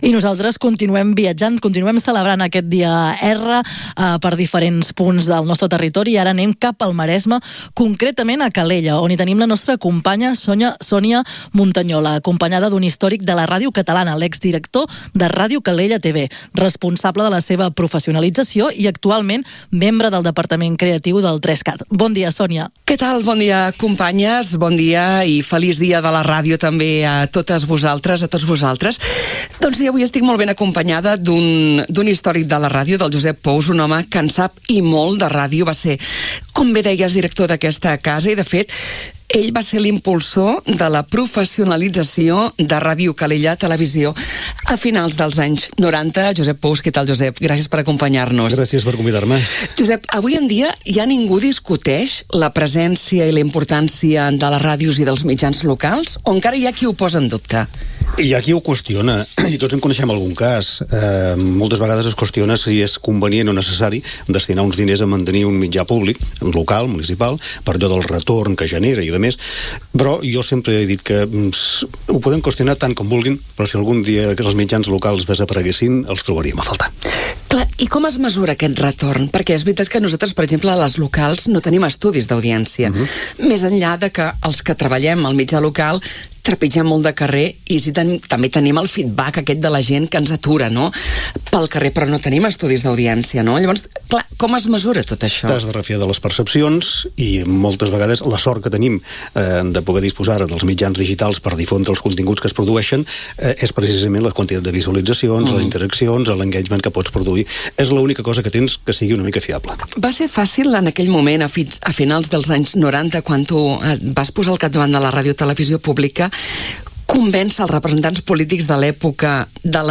Dos històrics de Ràdio Calella TV conversen en el Dia Mundial de la Ràdio.